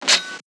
LOFI_Checkout_01_mono.wav